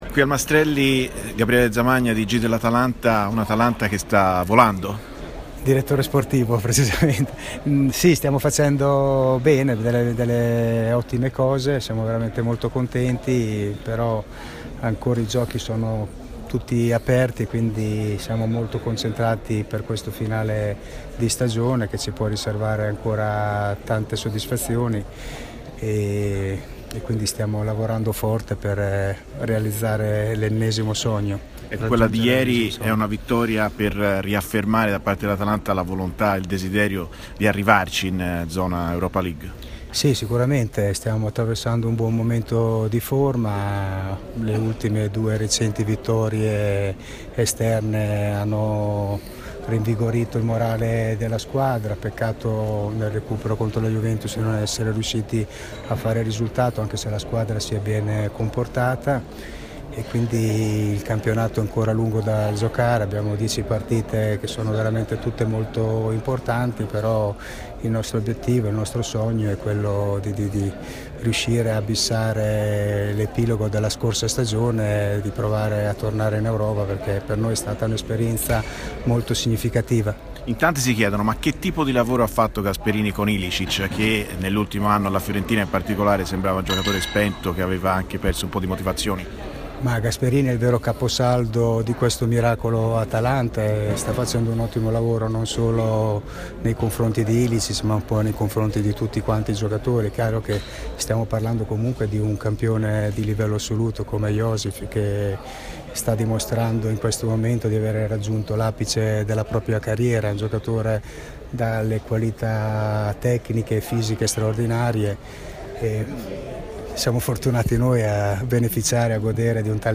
intervistato
al Premio Maestrelli